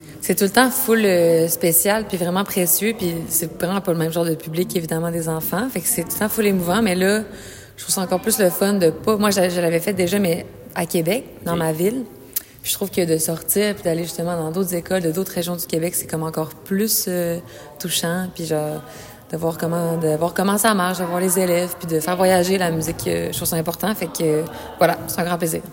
Lou-Adriane Cassidy explique sa perception du « Festif! à l’École ».